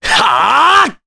Mitra-Vox_Attack4_jp.wav